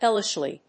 アクセント・音節héll・ish・ly